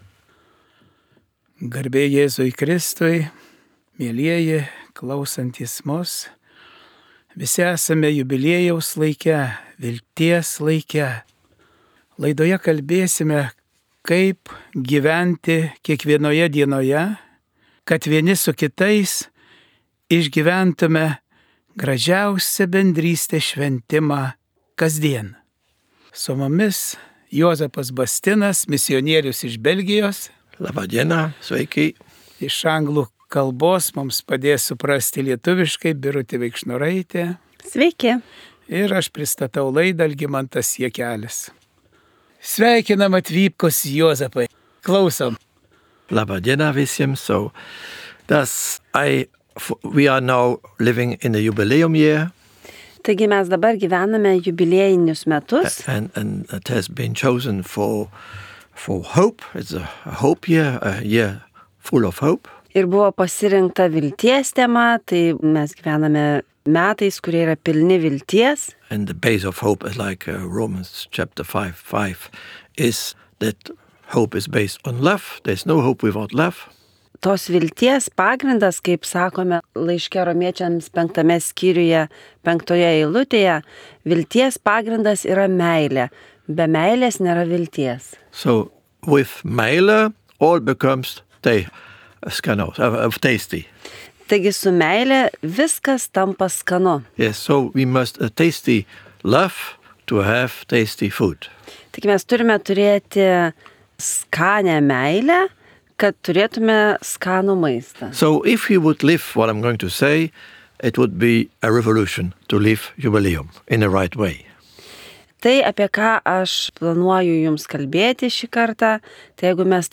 1 Katechezė